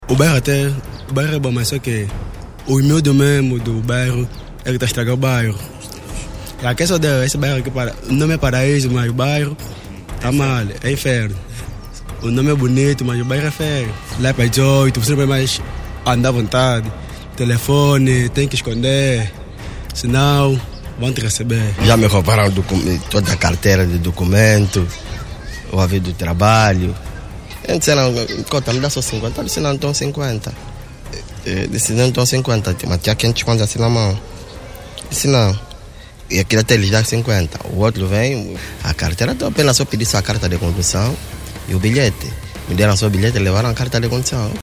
Em Março do ano passado, a Rádio Nova lá esteve em grande reportagem e conversou com alguns moradores sobre a criminalidade na zona.
PARAISO-VOX-POP.mp3